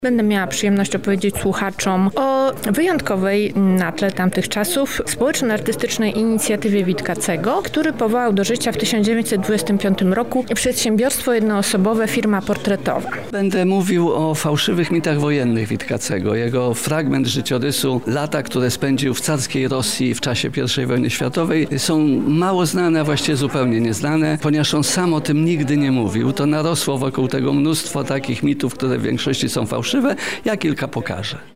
Organizatorzy.mp3